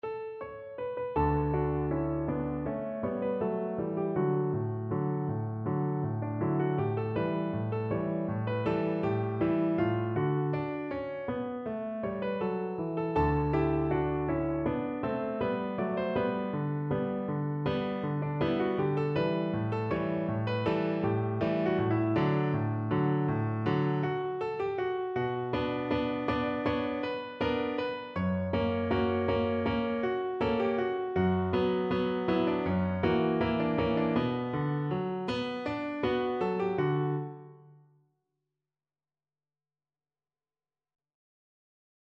MIDIWilliams, Henry F., The Parisian Waltzes, Introduction to Waltz No. 3, mm.1-16